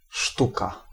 Ääntäminen
IPA : /ˈpleɪ/